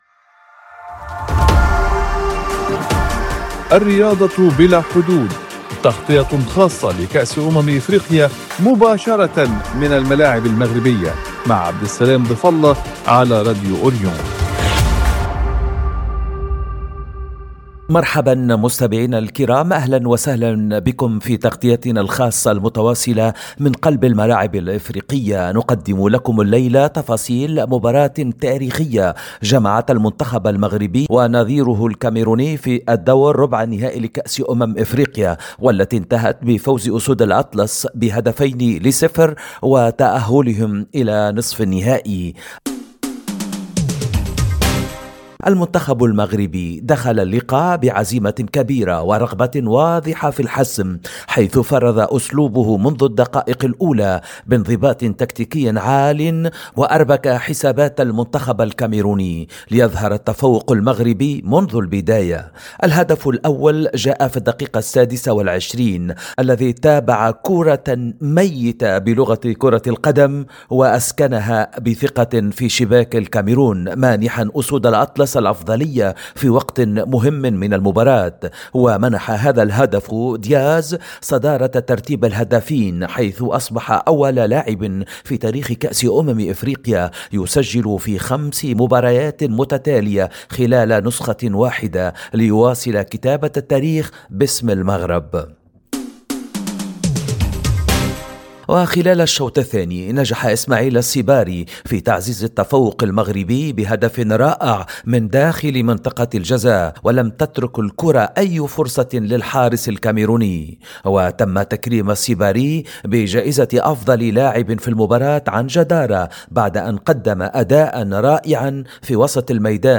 وفي استطلاع لآراء المشجعين، أهدى الجميع هذا الفوز التاريخي إلى صاحب الجلالة الملك محمد السادس والأسرة العلوية، معربين عن تفاؤلهم الكبير ببقاء الكأس الغالية في المغرب.